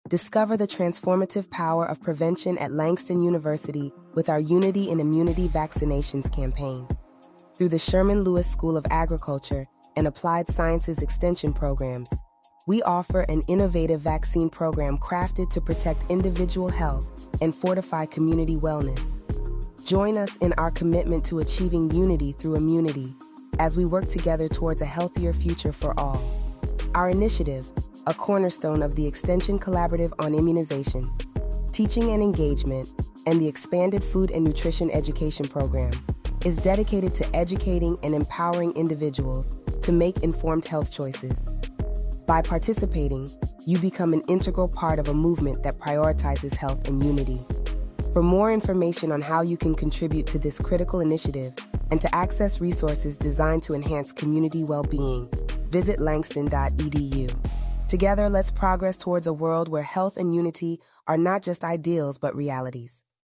RADIO COMMERCIAL